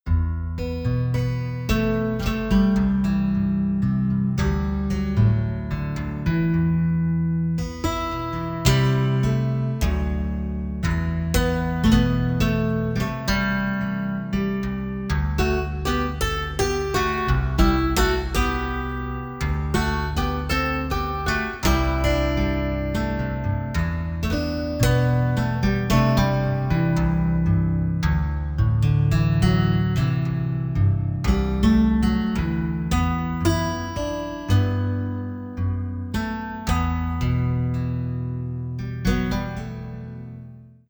Steel guitar (bucle)
guitarra
melodía
repetitivo
rítmico
sintetizador